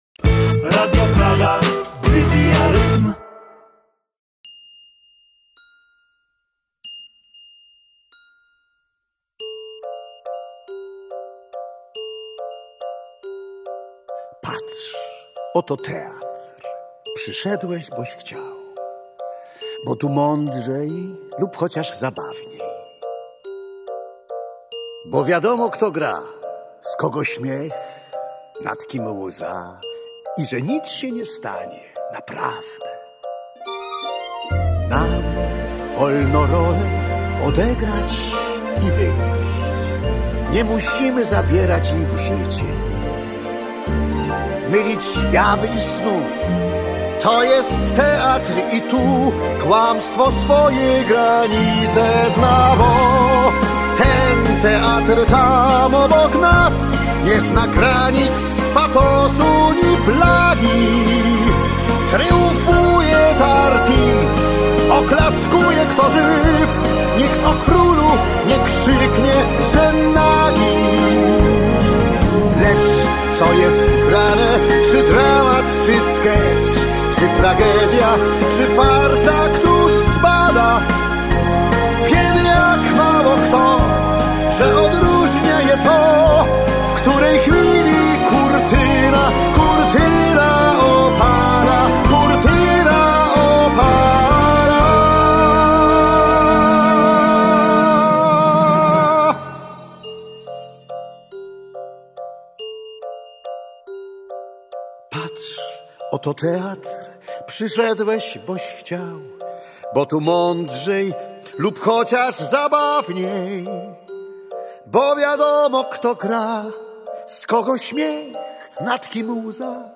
Wspaniały to duet do rozmowy.